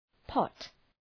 Προφορά
{pɒt}